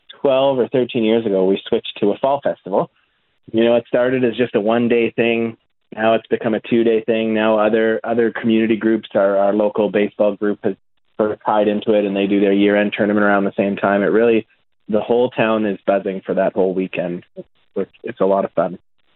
Penhold Mayor Mike Yargeau spoke with CFWE about what attendees can expect when they head down to Penhold’s Fall Festival Celebration.